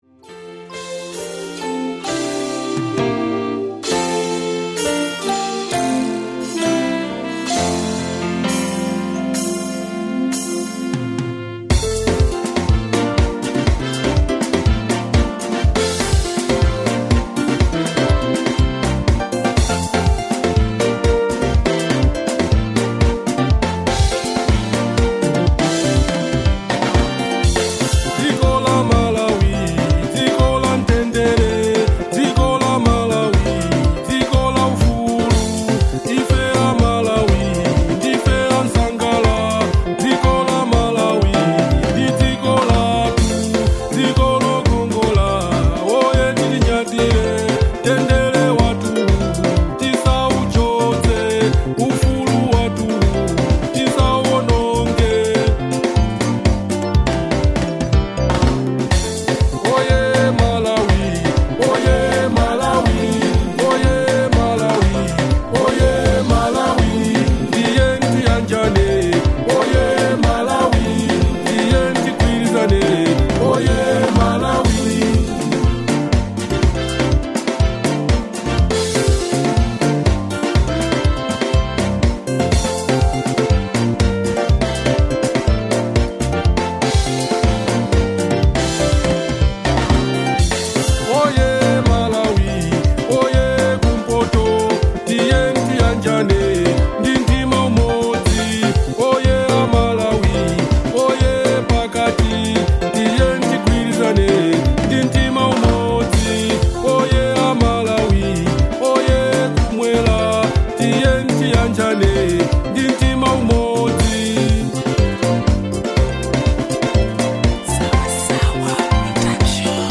Genre : Local